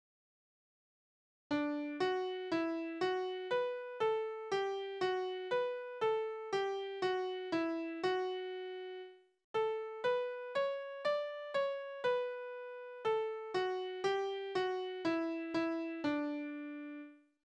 Tonart: D-Dur
Taktart: 4/4
Tonumfang: Oktave
Besetzung: vokal